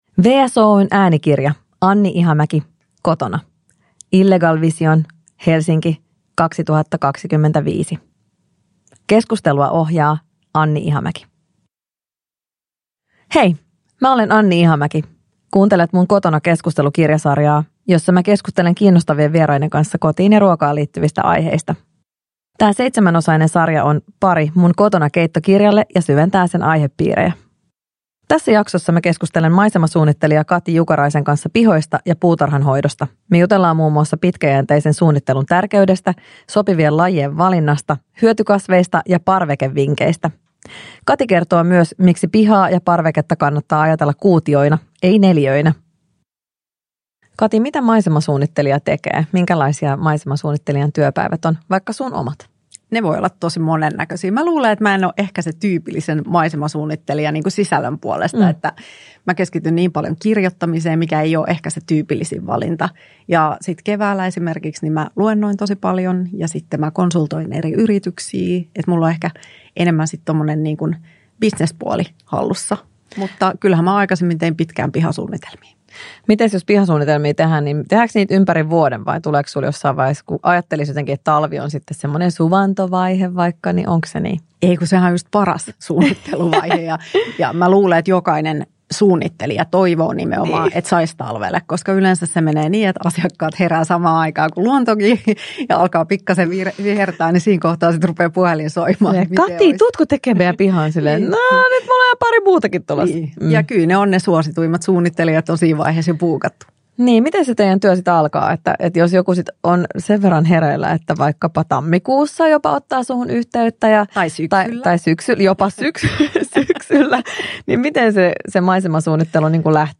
Kotona – Keskusteluja. Puutarhassa – Ljudbok